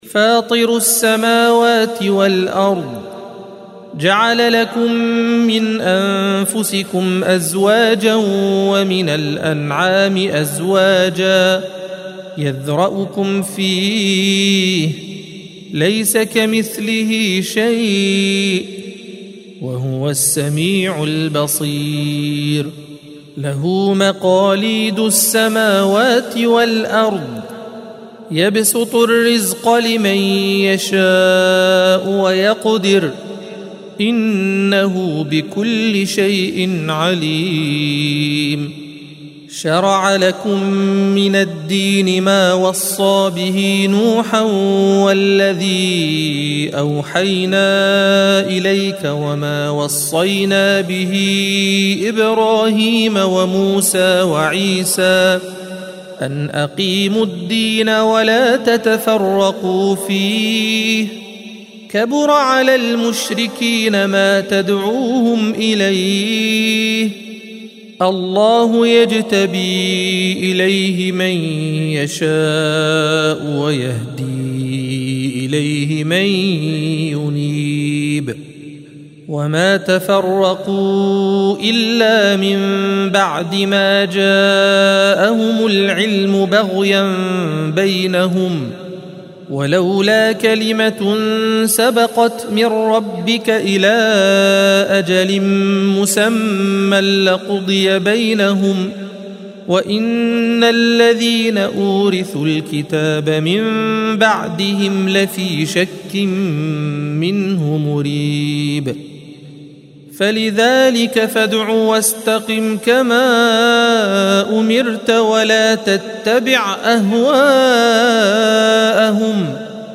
الصفحة 484 - القارئ